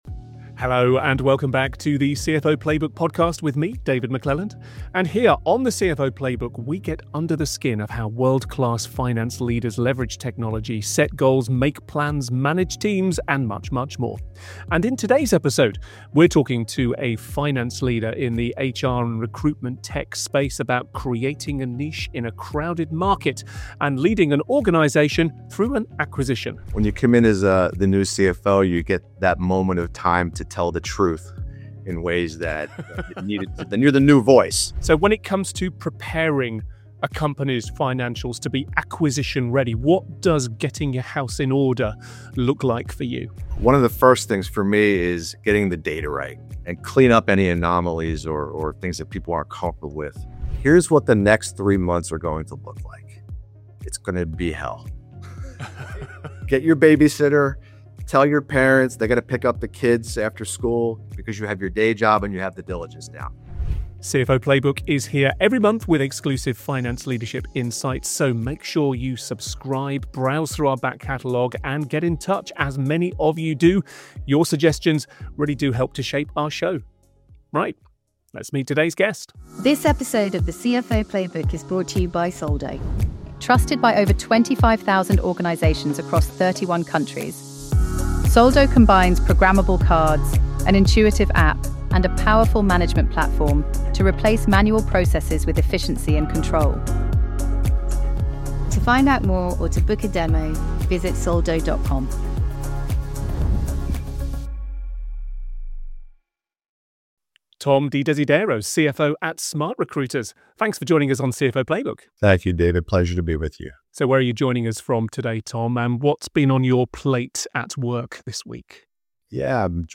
The CFO Playbook features interviews with world-class CFOs, finance leaders and founders from some of the fastest growing companies.